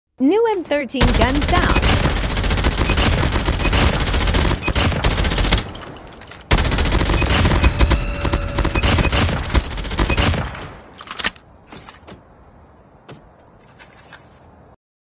NEW M13 GUN SOUND 🤩 sound effects free download